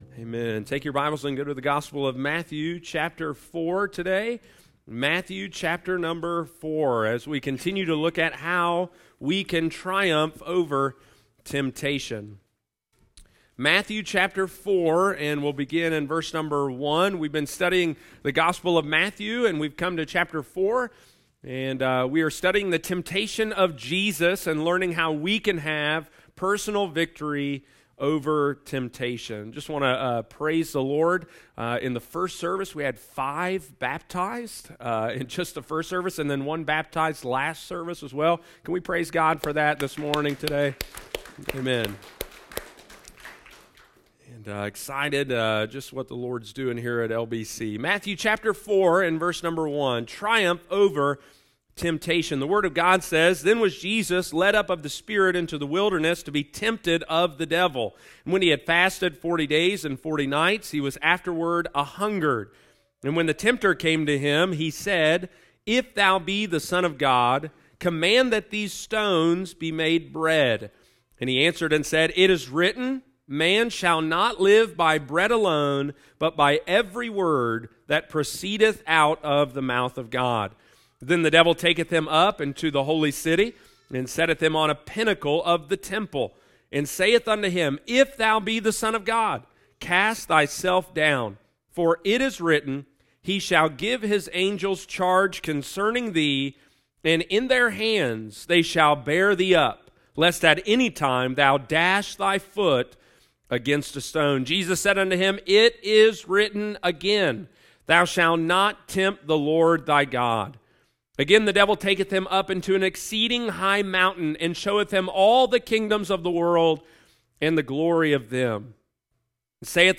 Triumph Over Temptation Part 2 – Lighthouse Baptist Church, Circleville Ohio